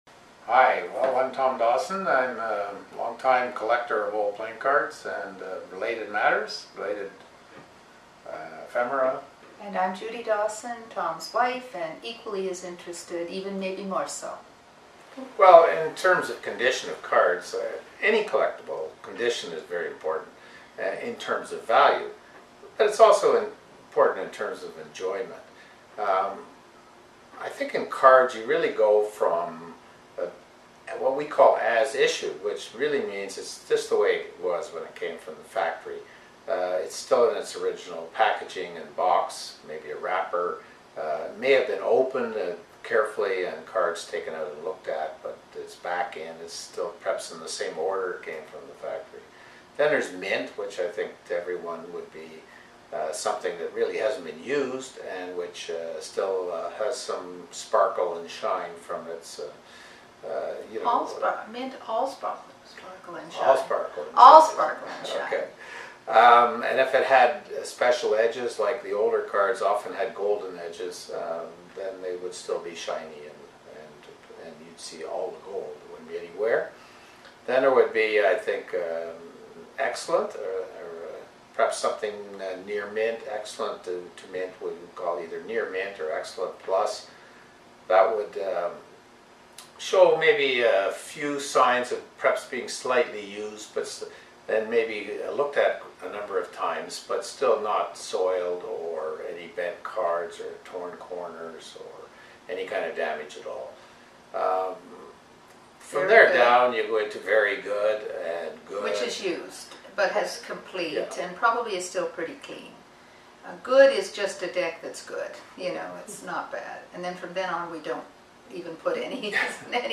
An Interview About Grading Cards